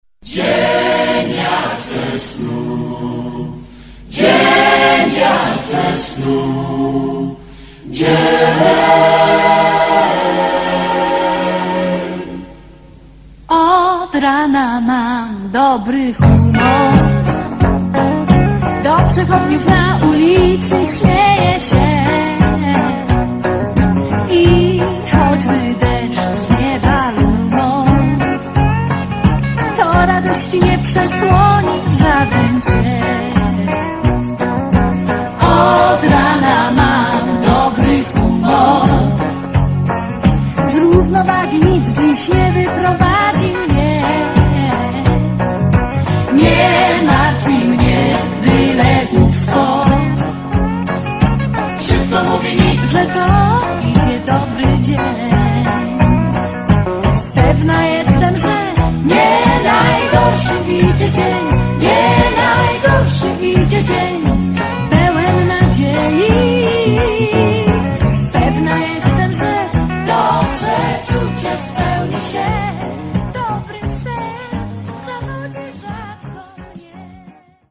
DUET
VOC GUITAR KEYB BASS DRUMS TEKST